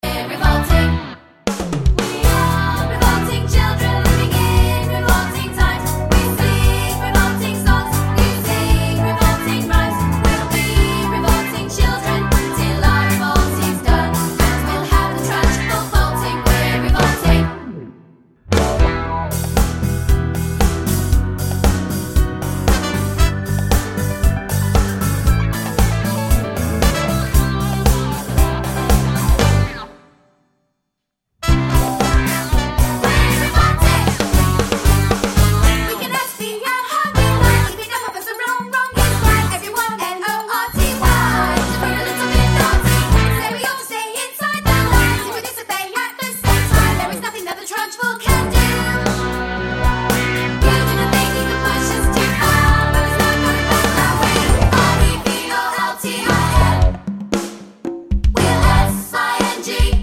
Musicals